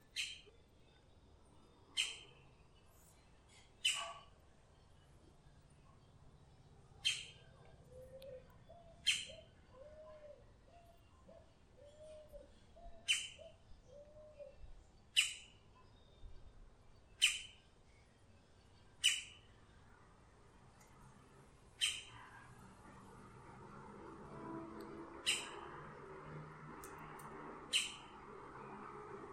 Scientific name: Colaptes melanochloros melanolaimus
English Name: Green-barred Woodpecker
Location or protected area: Gran Buenos Aires Norte
Condition: Wild
Certainty: Observed, Recorded vocal